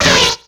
Cri de Munja dans Pokémon X et Y.